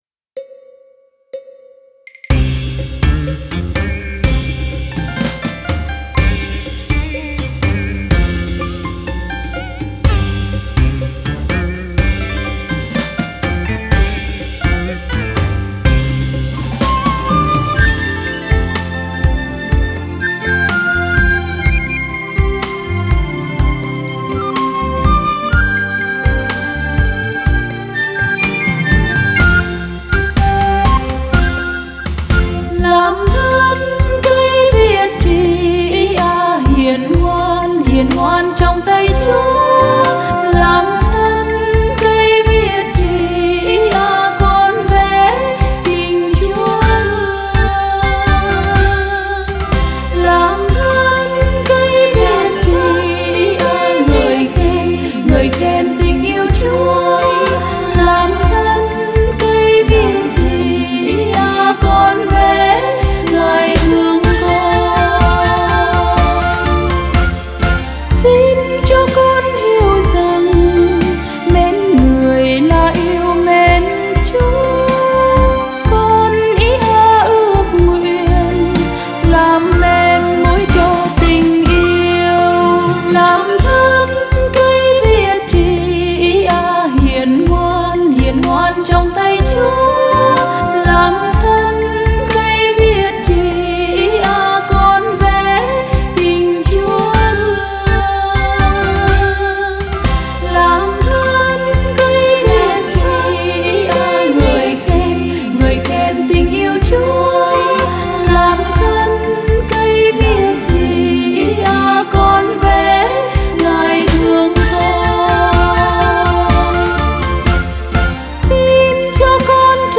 Thánh Ca: Làm Thân Cây Viết Chì.